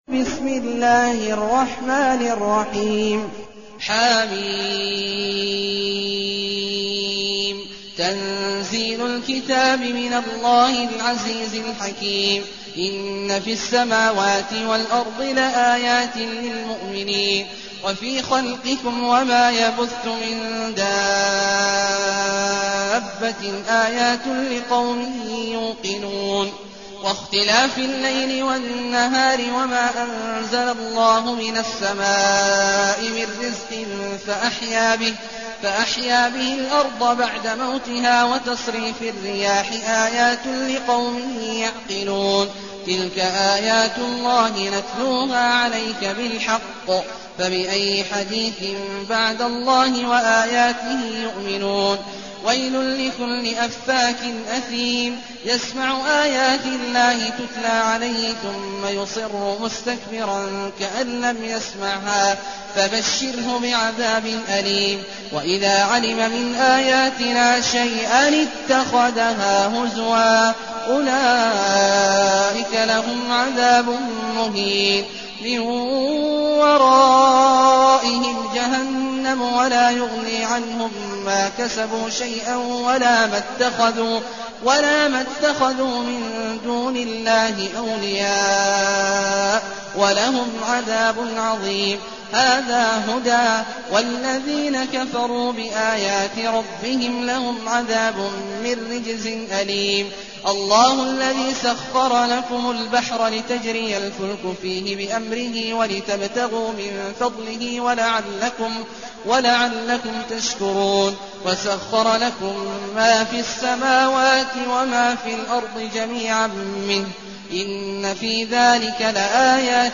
المكان: المسجد الحرام الشيخ: عبد الله عواد الجهني عبد الله عواد الجهني الجاثية